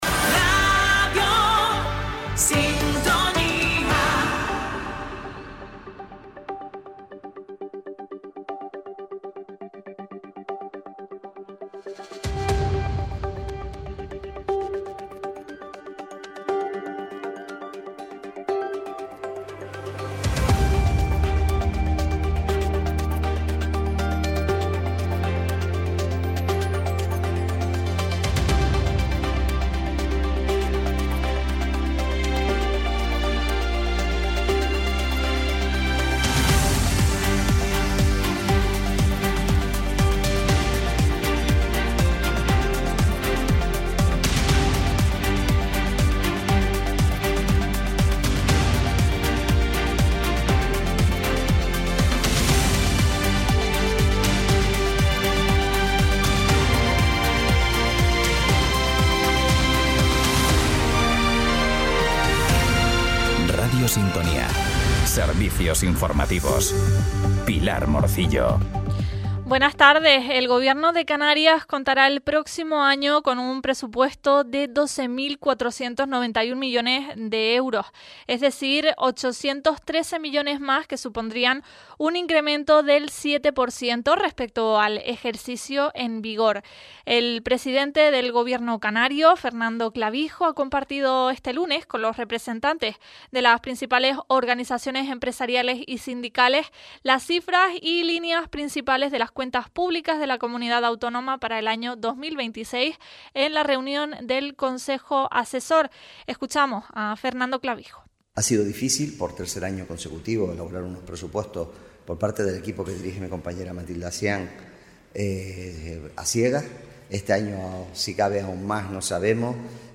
Cada día, desde Radio Sintonía, puedes seguir toda la información local y regional en nuestro espacio informativo. En él te contamos, en directo, las noticias más importantes de la jornada, a partir de las 13:15h.